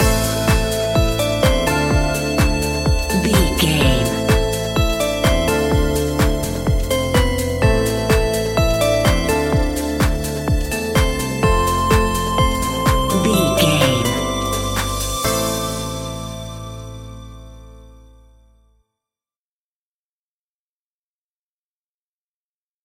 Aeolian/Minor
groovy
uplifting
futuristic
happy
drum machine
synthesiser
bass guitar
funky house
deep house
nu disco
upbeat
synth bass
synth leads